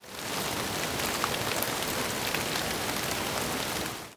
rain6.ogg